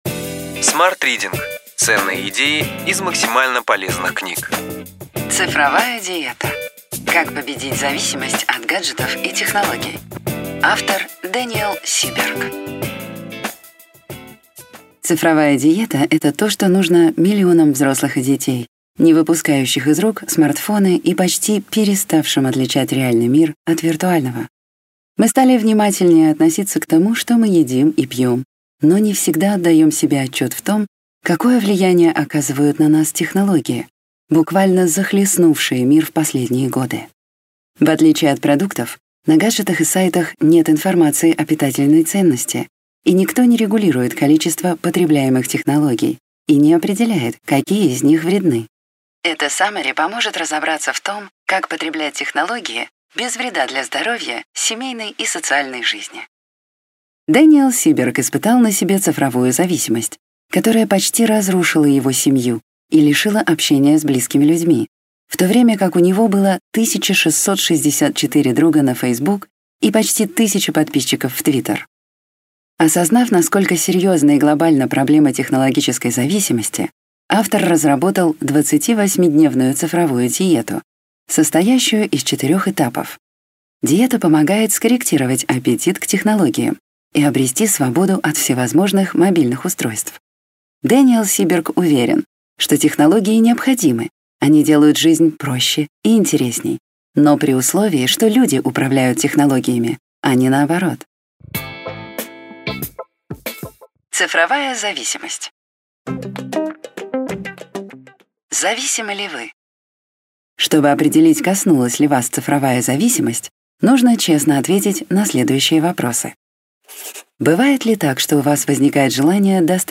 Аудиокнига Ключевые идеи книги: Цифровая диета. Как победить зависимость от гаджетов и технологий.